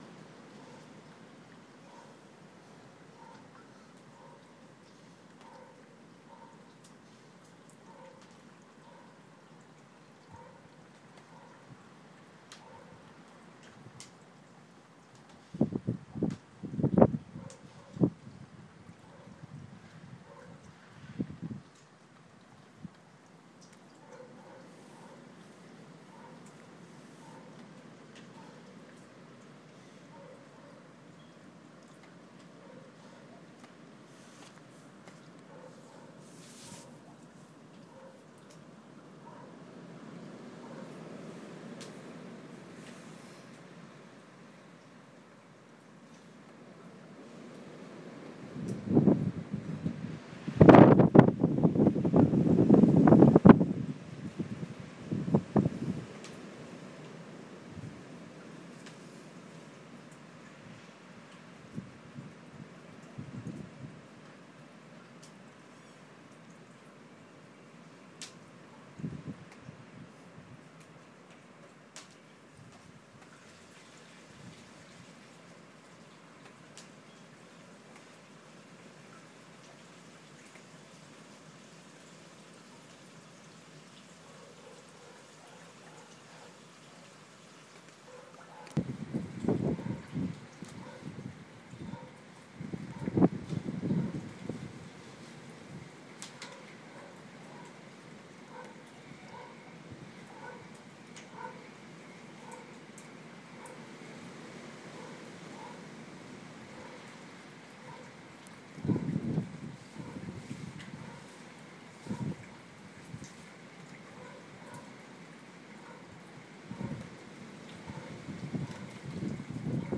Ambience: Out in the wind and rain